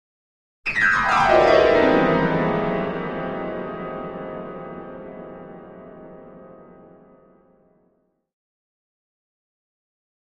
Piano Dramatic Descending Play